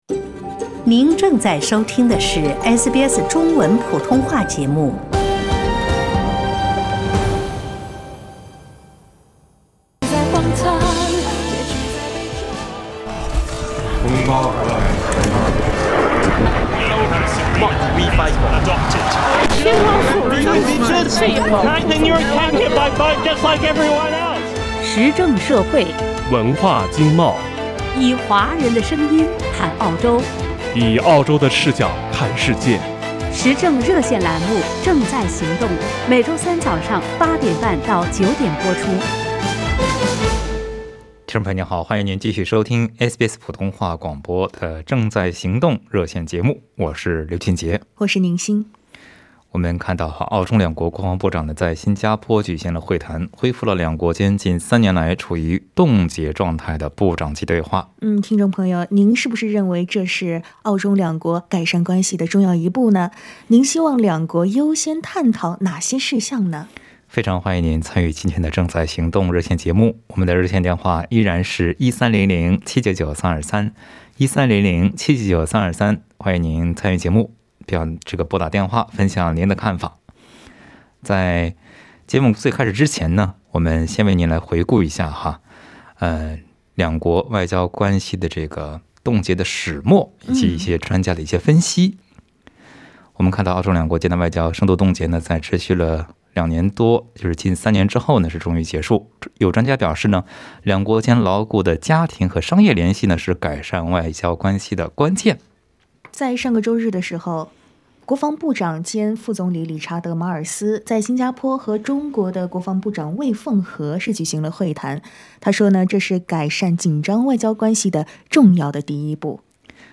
在本期《正在行动》热线节目中，听友们就澳中两国恢复部长级对话分享了各自的看法，并对澳中两国沟通和谈条件方式、政府的政策侧重、新冠病毒溯源等问题表达了自己的观点。